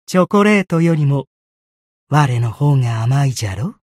觉醒语音 比起巧克力，吾更甜美不是吗？